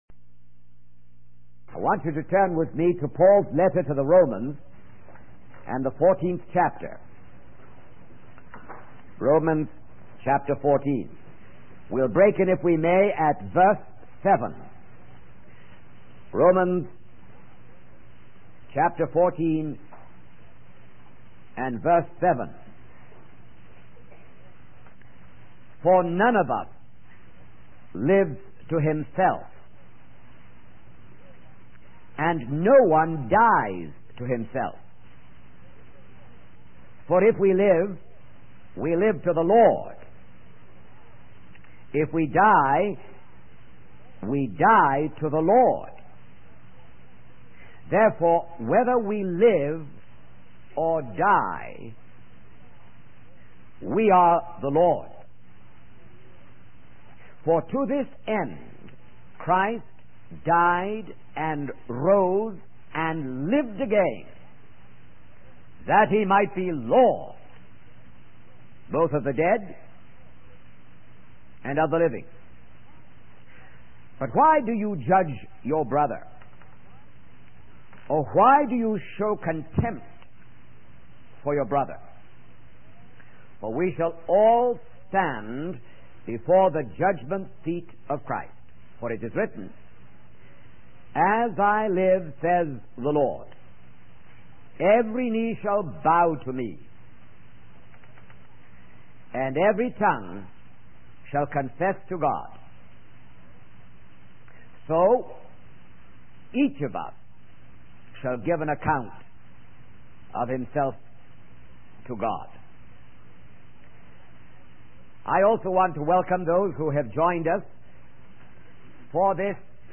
In this sermon, the preacher emphasizes the importance of being accountable to God in both life and death.